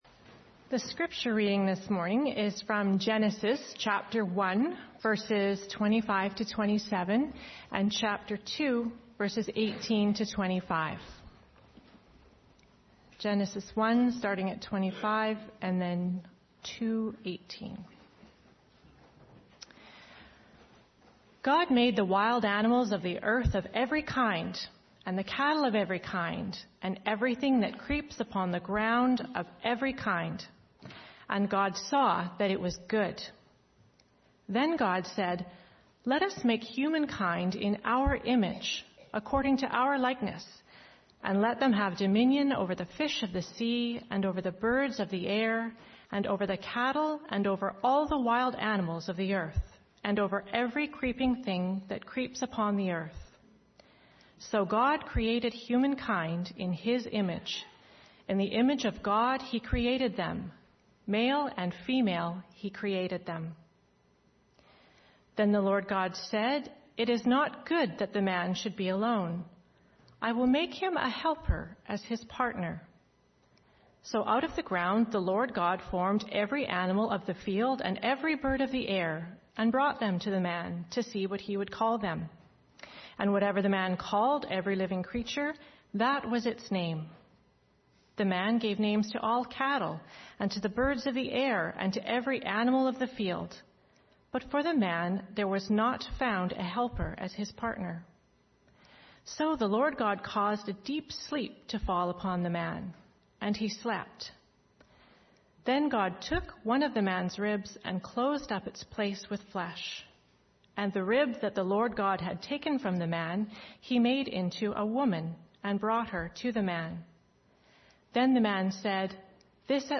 Sermons | Olivet Baptist Church
Guest Speaker